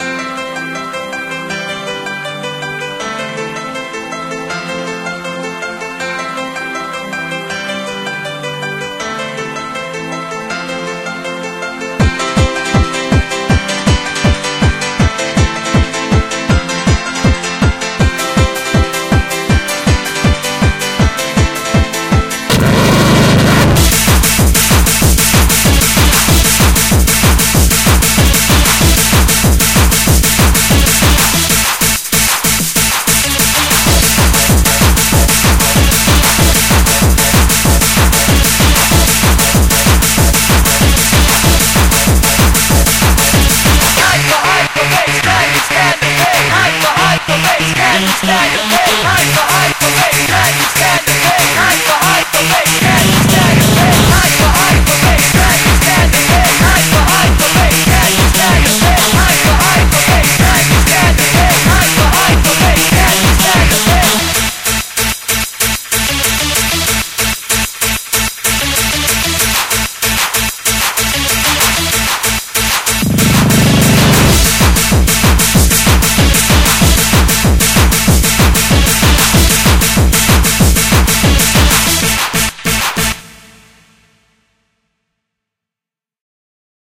BPM160-190
Audio QualityPerfect (High Quality)